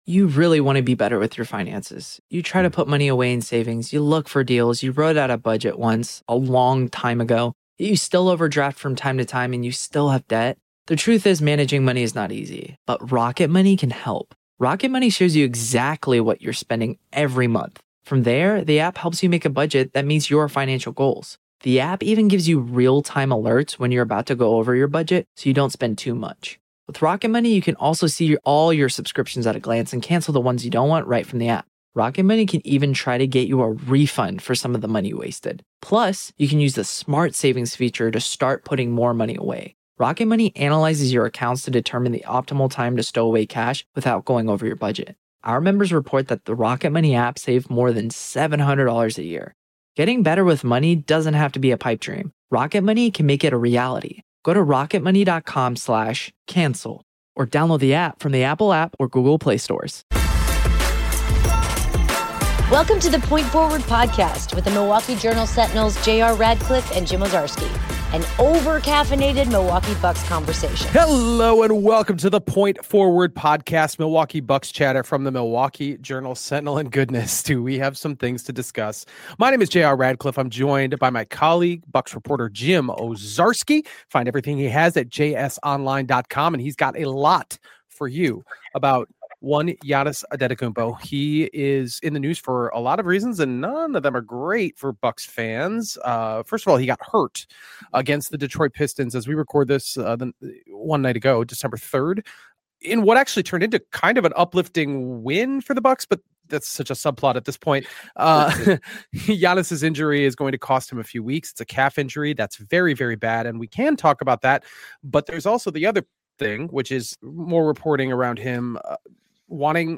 Music intro